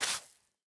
brushing_sand1.ogg